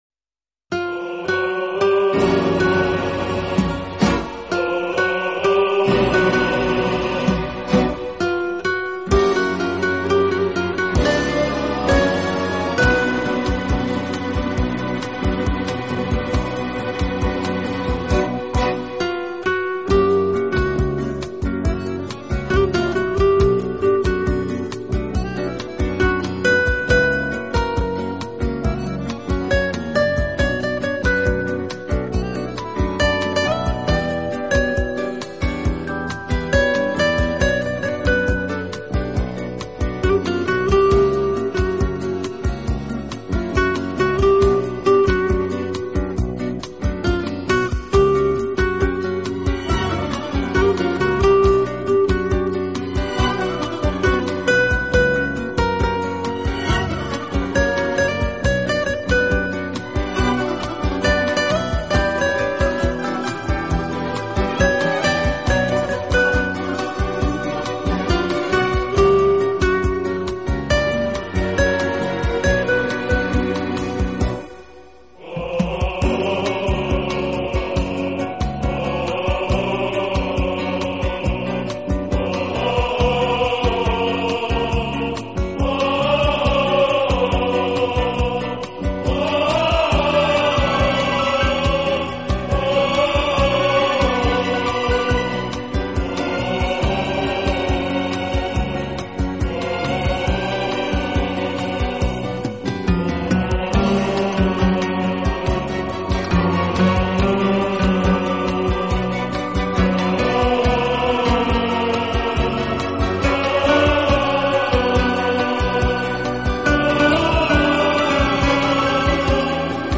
俄罗斯音乐改编的吉他曲集，相信有许多是你耳熟能详的。